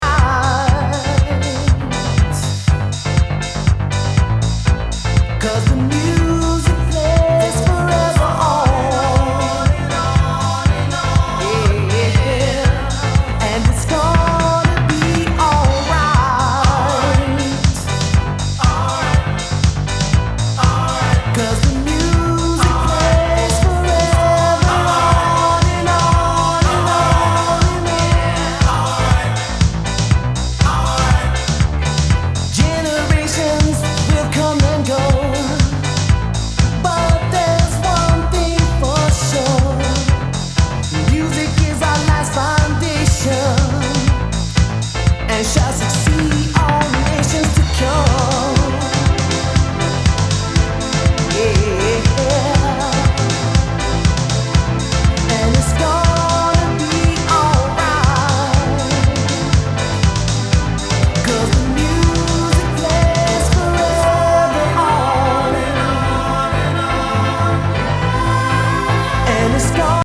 house classics